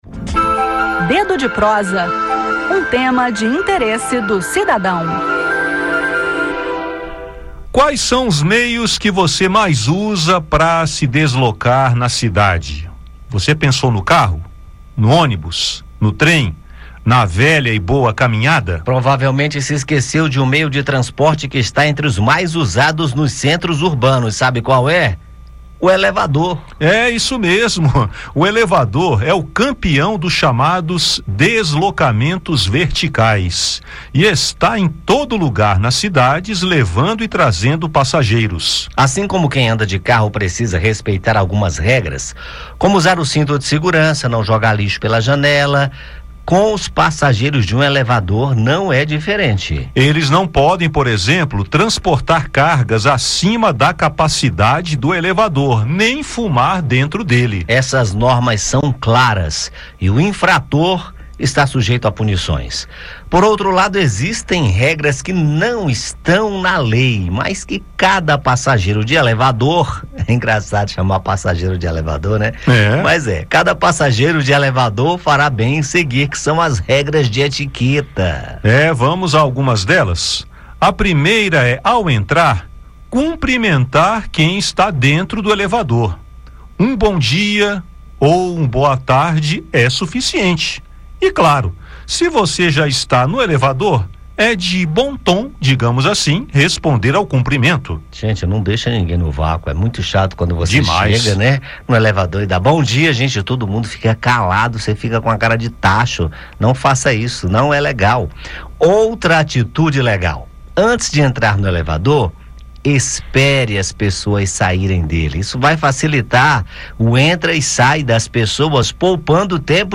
No bate-papo, falaremos de algumas regras de uso que não estão na lei, mas que a boa educação, a empatia e o respeito exigem: as regras de etiqueta.